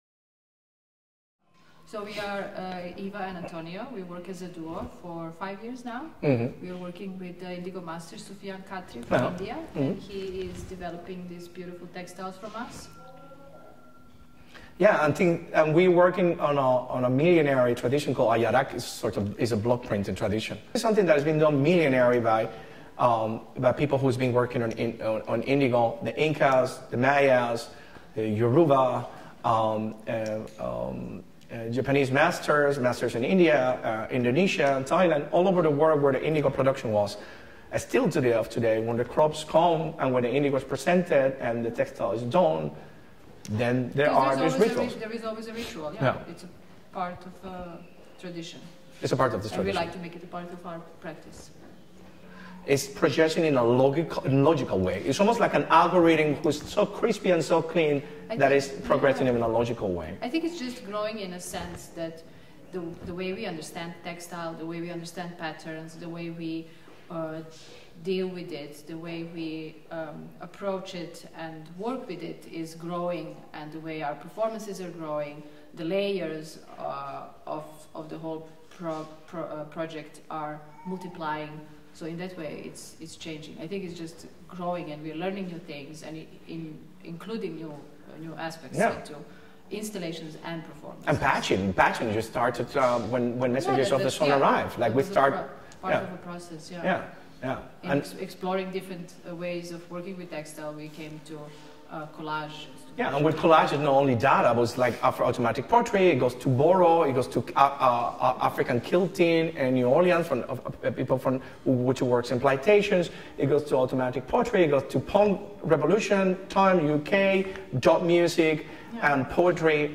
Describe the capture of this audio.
Interview - Stedelijk Museum